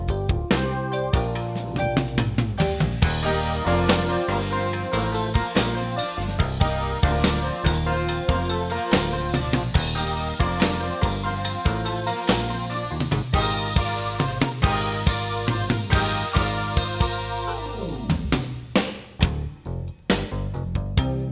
Figure 2 – A passage of clipped music. The crest factor is about 14 dB.
Wave File 2 – Clipped music.
clipped_waveform.wav